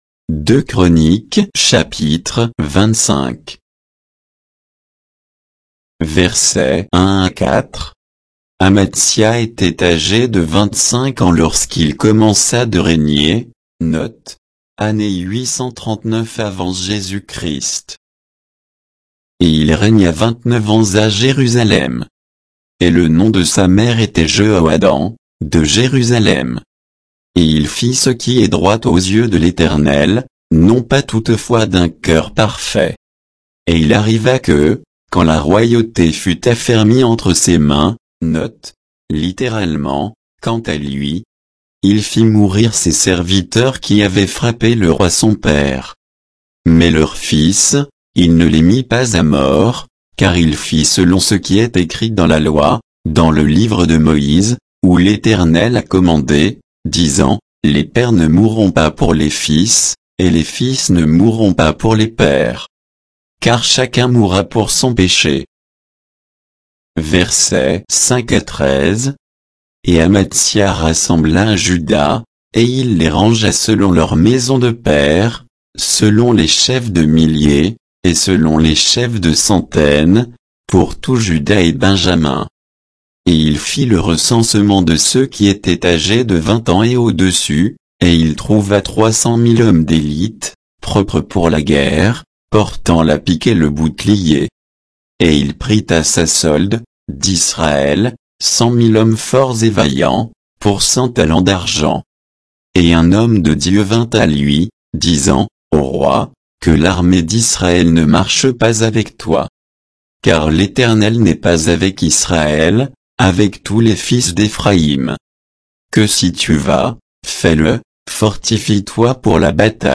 Bible_2_Chroniques_25_(avec_notes_et_indications_de_versets).mp3